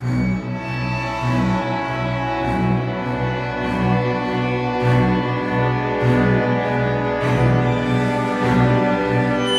狼嚎声
标签： 如何L 嗥叫 狼人
声道立体声